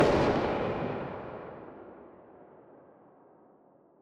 riflerev.wav